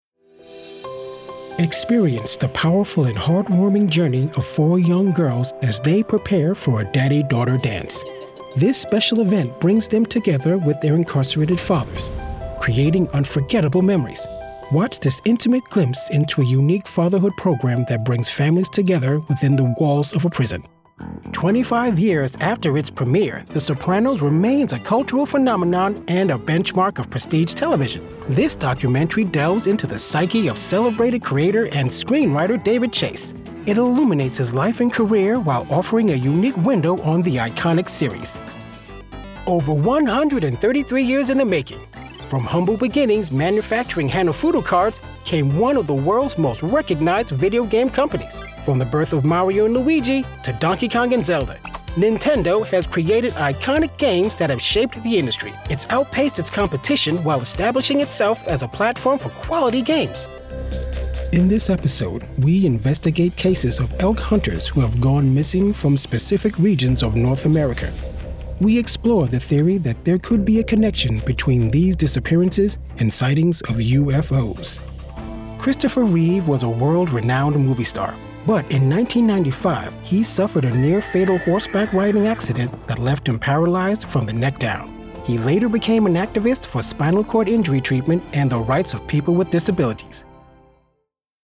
Voice Acting
documentary-vo-demo.mp3